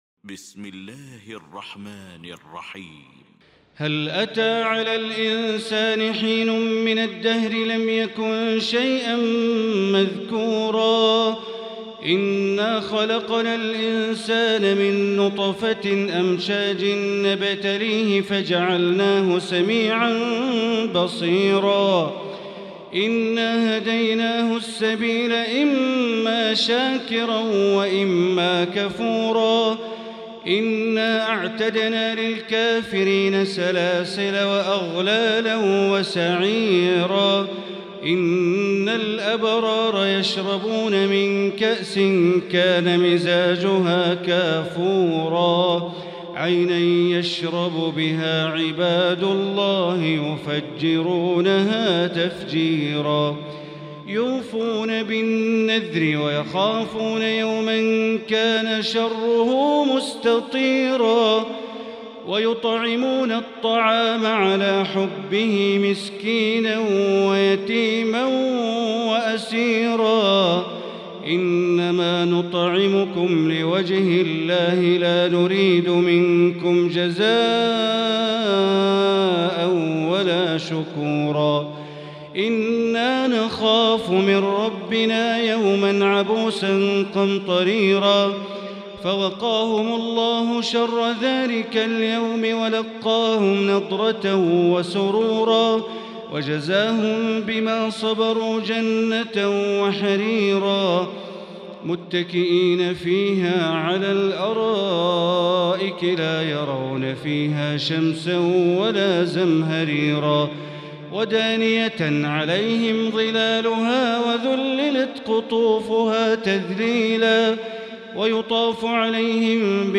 المكان: المسجد الحرام الشيخ: معالي الشيخ أ.د. بندر بليلة معالي الشيخ أ.د. بندر بليلة الإنسان